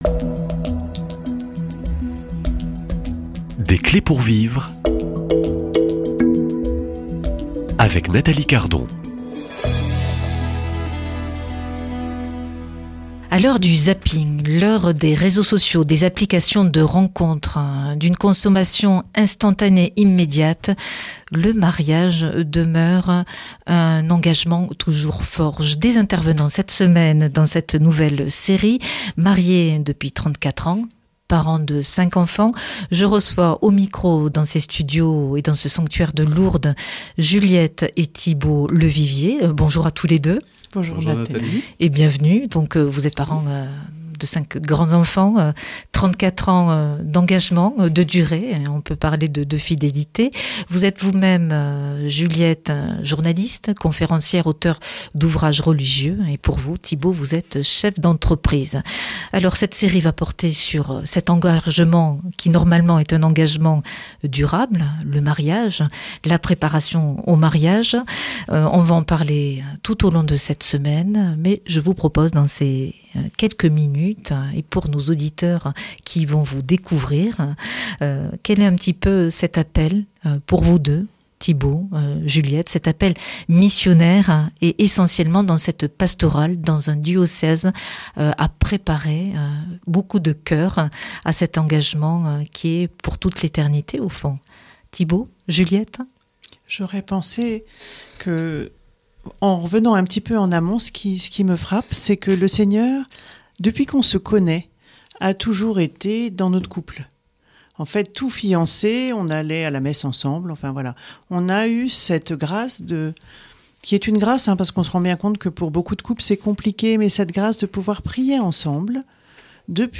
[ Rediffusion ] Heureux ceux qui s’aiment - Préparation au mariage (Volet 1) Heureux, bienheureux ceux qui s’aiment et veulent s’aimer toute leur vie !
Une émission présentée par